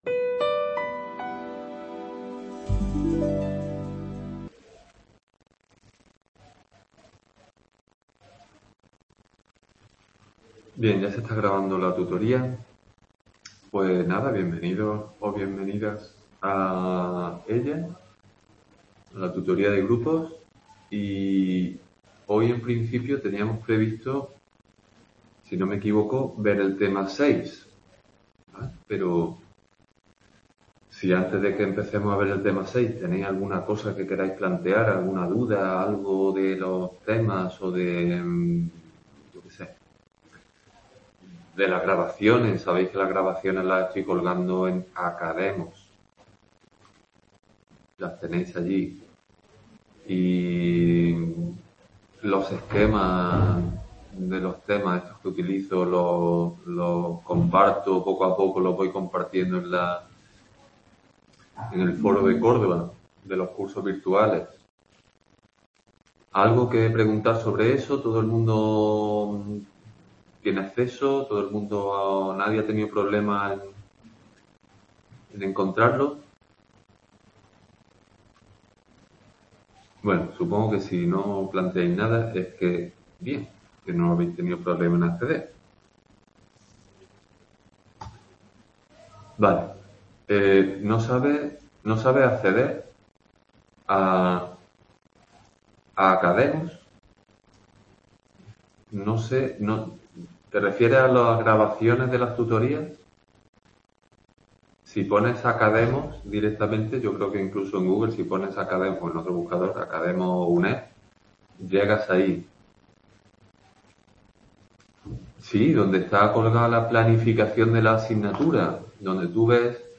Tutoría Grupos. Tema 6: Productividad | Repositorio Digital